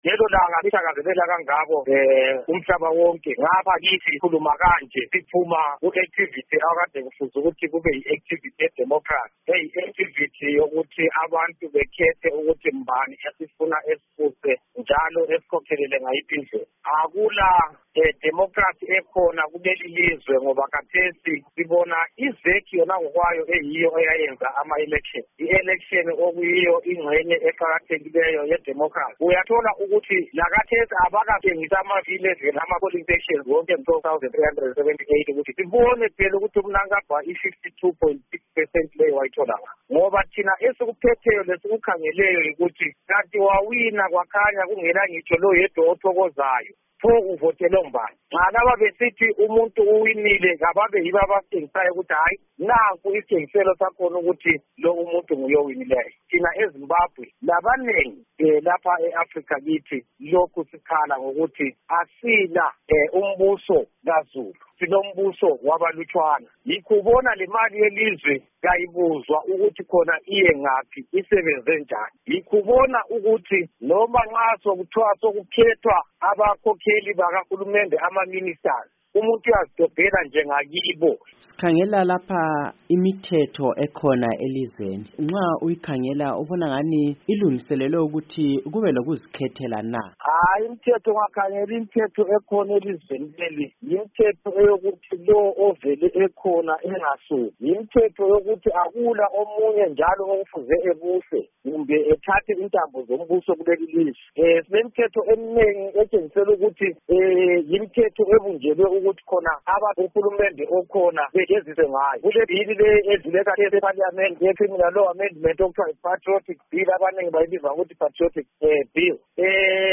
Ingxoxo loMnu Dubeko Sibanda ngelanga leInternational Day of Democracy.wav